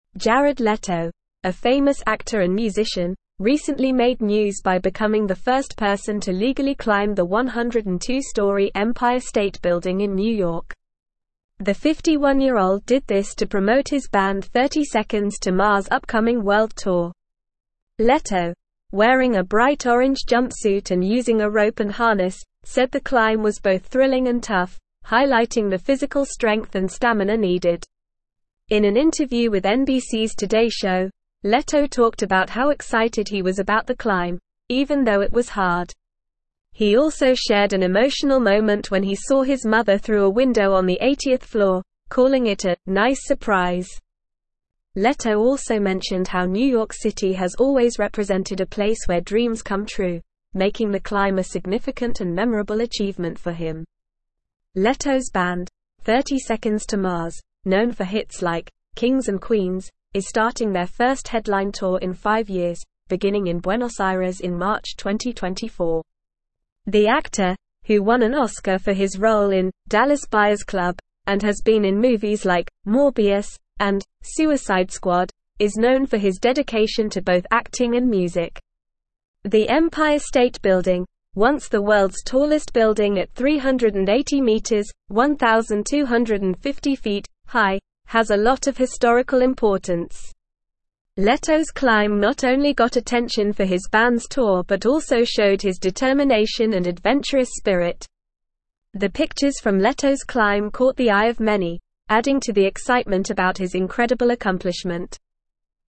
Normal
English-Newsroom-Upper-Intermediate-NORMAL-Reading-Jared-Leto-Scales-Empire-State-Building-for-Band.mp3